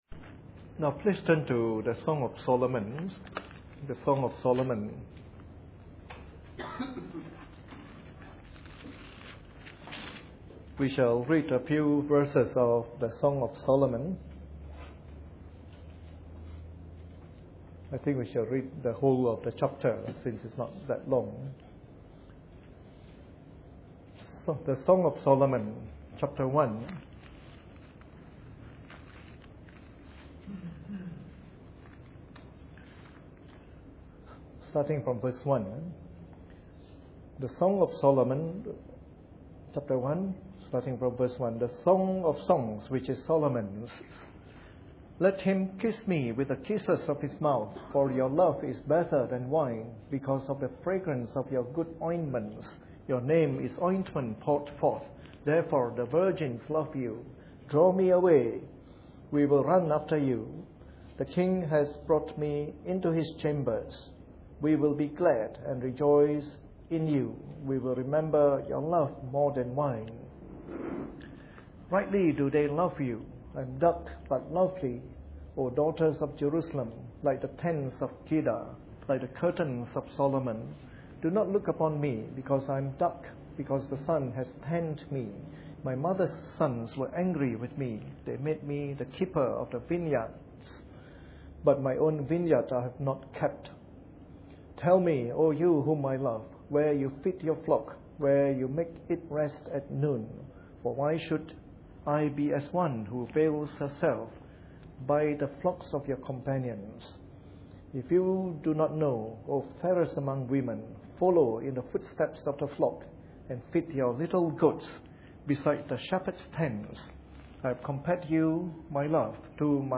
Preached on the 21st of September 2011 during the Bible Study from our new series on the Song of Solomon.